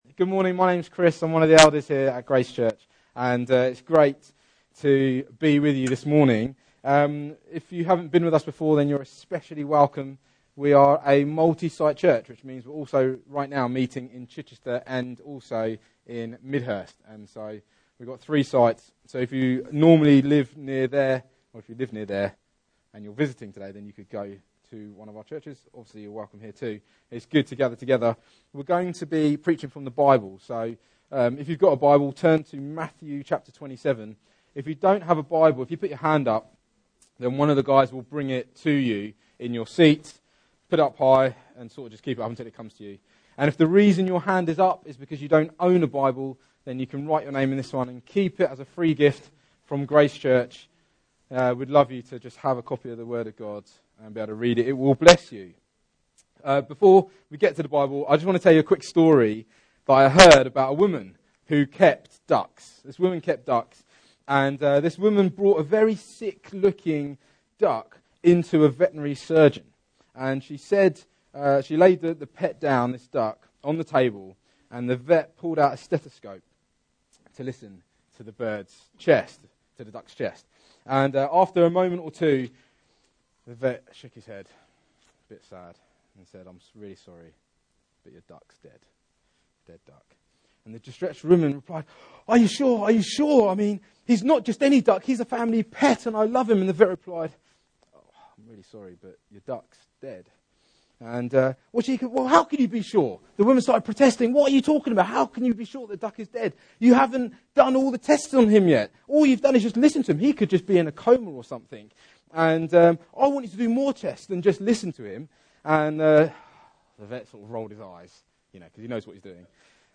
Series: Miscellaneous Sermons 2017